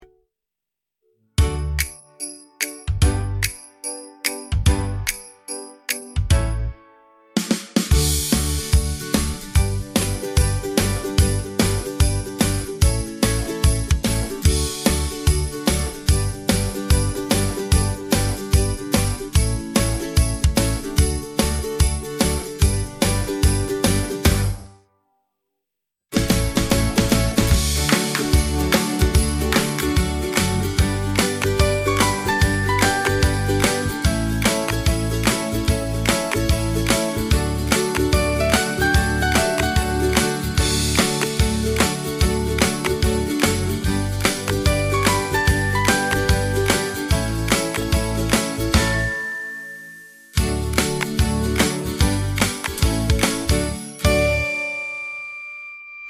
Versão instrumental,